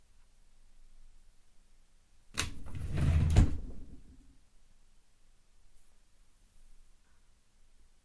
Fast/ Open Drawer 2
Duration - 7 s Environment - Bedroom, absorption of curtains, carpet and bed. Description - Open, pulled fast, grabs, slams, wooden drawer, rails hits end when fully opened.